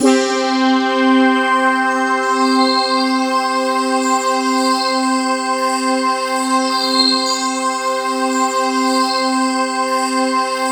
BRASSPADC4-R.wav